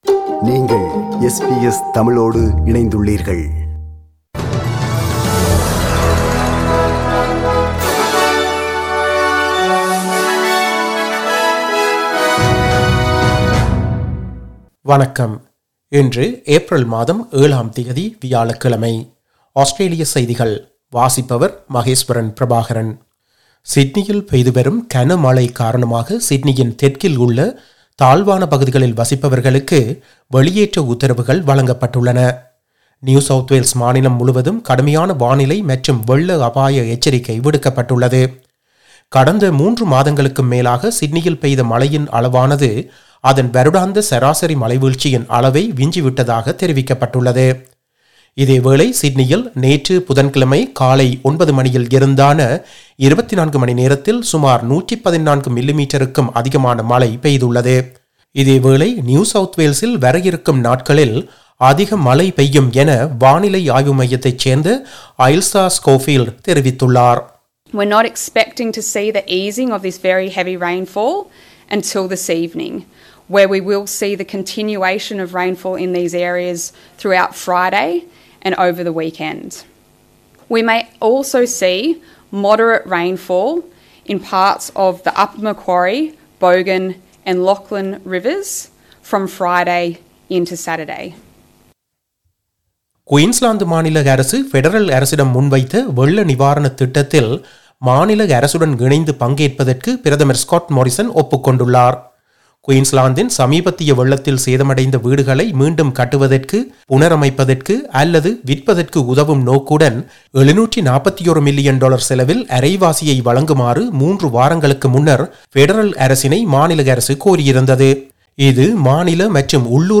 Australian news bulletin for Thursday 07 April 2022.